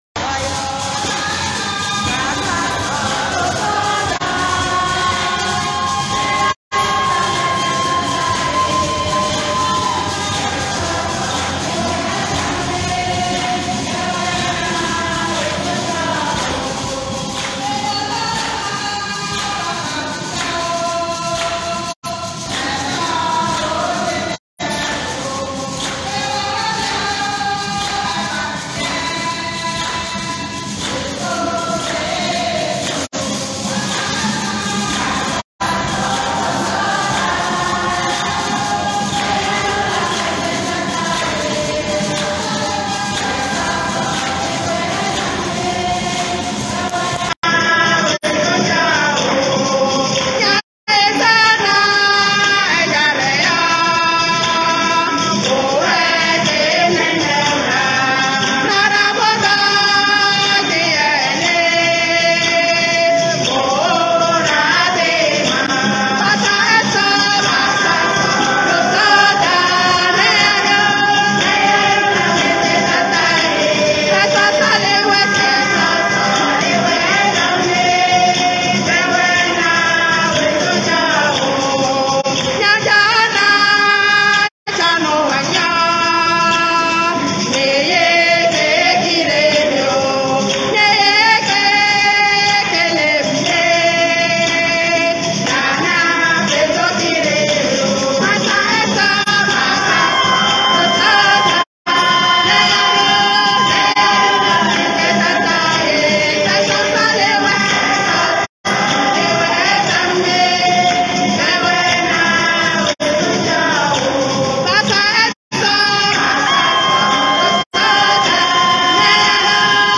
La messe Chrismale fut célébrée le samedi 1er avril 2023
en la cathédrale St Pierre St Paul de Kara
ob_c6cc03_chant-d-entree.m4a